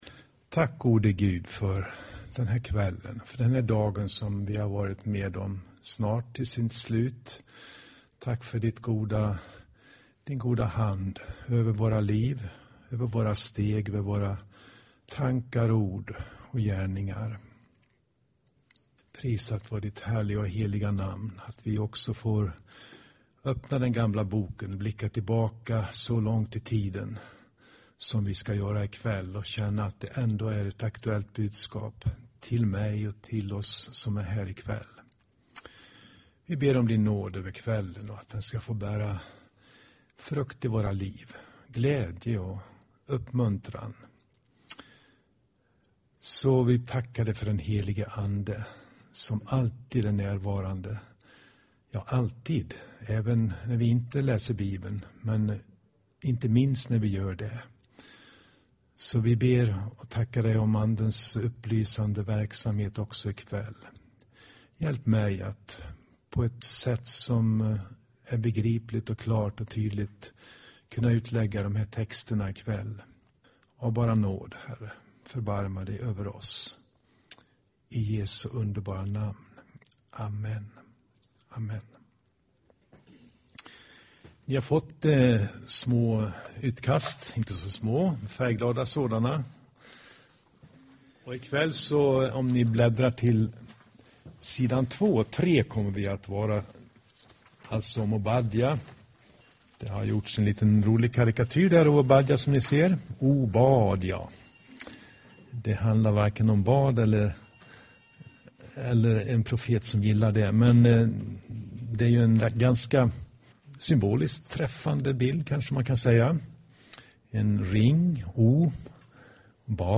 Inspelad i Tabernaklet i Göteborg 2012-10-24.